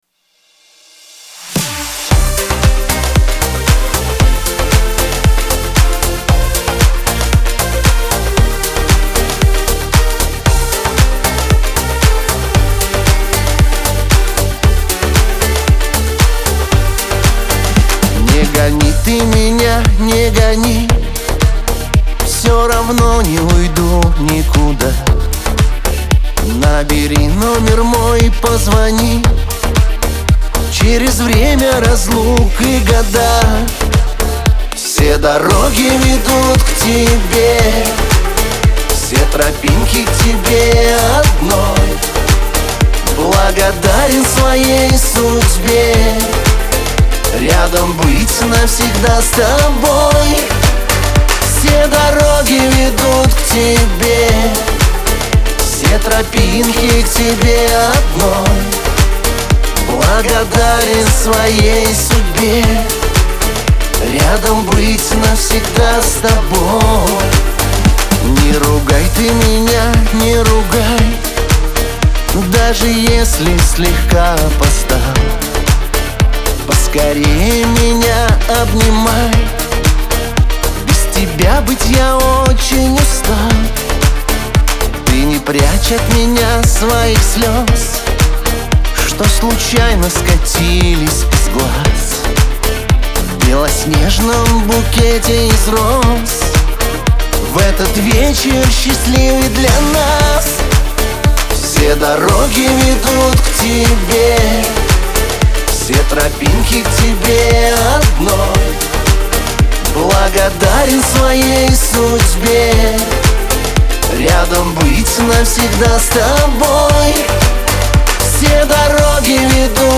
диско
pop , эстрада